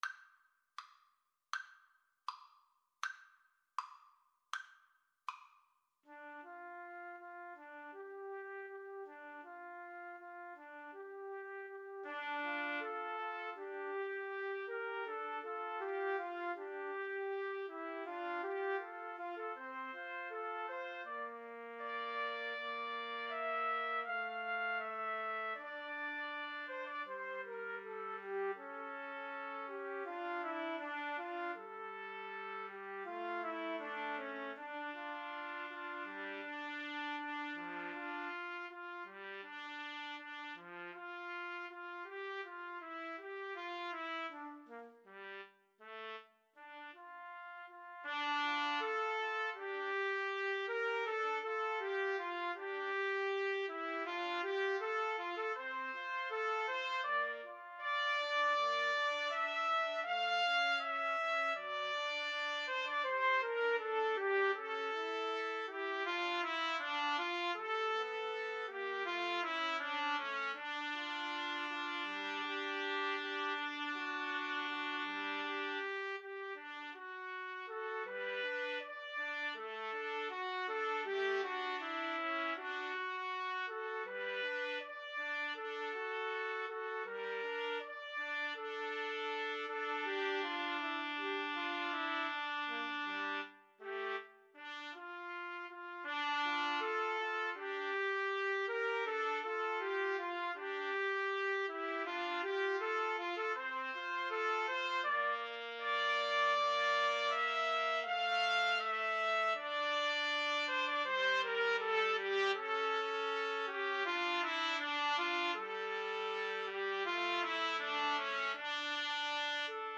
~ = 100 Andante
Classical (View more Classical Trumpet Trio Music)